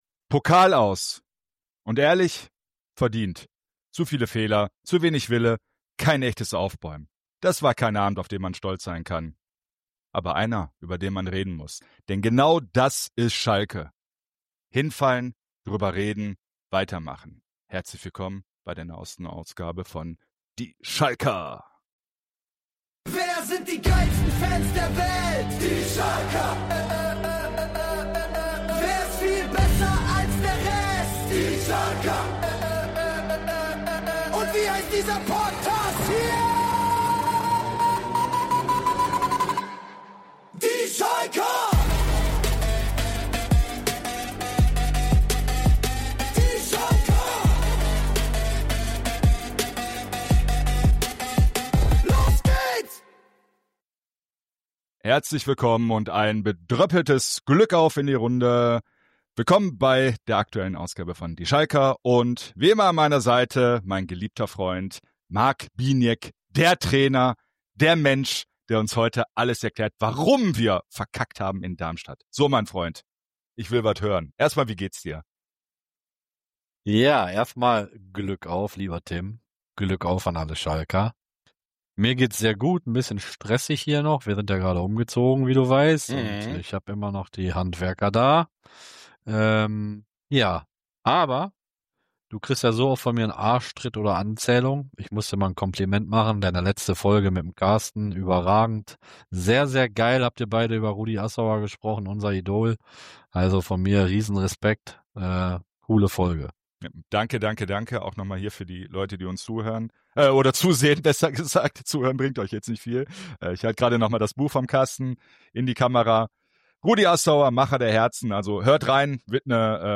Dazu gibt’s wie immer: Stimmen aus dem Stadion Ein Blick auf die Pokalabende unserer Kumpel-Vereine Und natürlich das legendäre Gewinnspiel: Ergebnis tippen & Blaue Männer-Armband abstauben!
Laut.